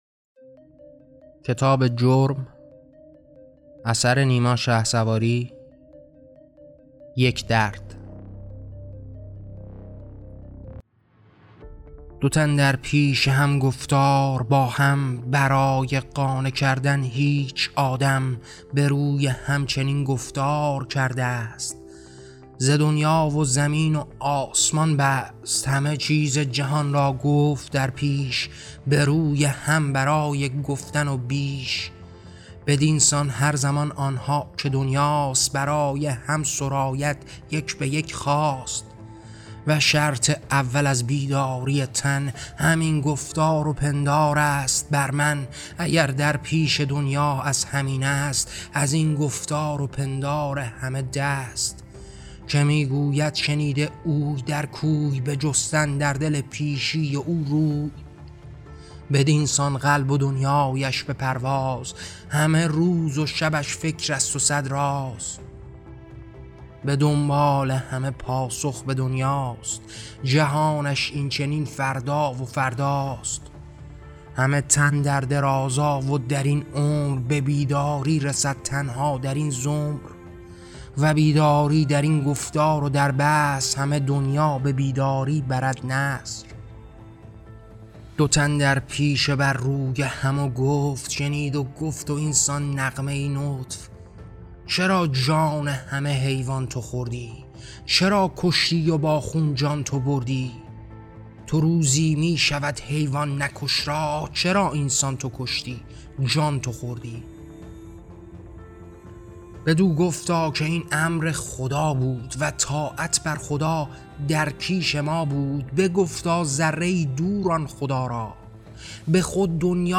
کتاب شعر صوتی جورم؛ داستان یک درد: واکاوی برابری جان و نقد خشونت